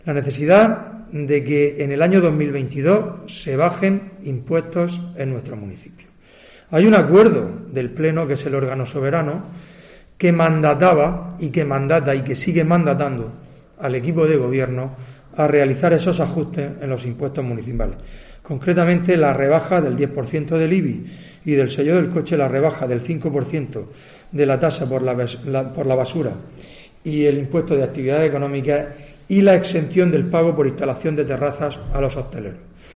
Fulgencio Gil, portavoz del PP en Lorca